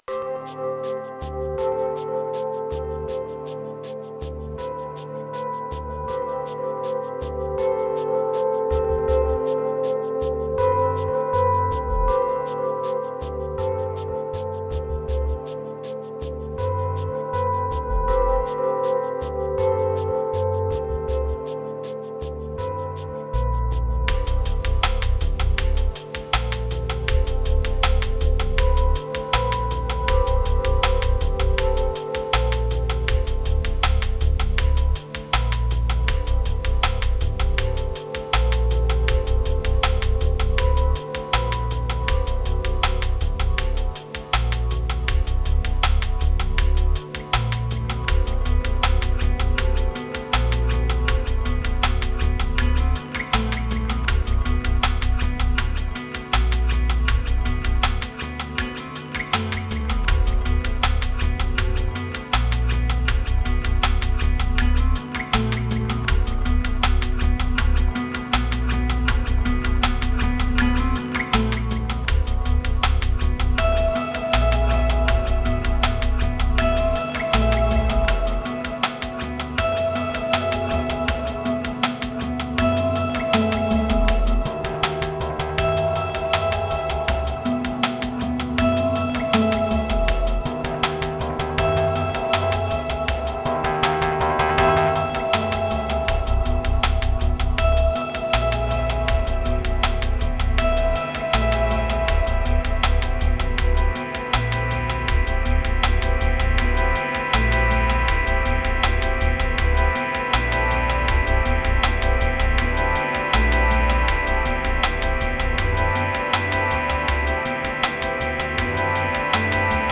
Riddim Abuse Guitars Triphop Relief Sacred Weekend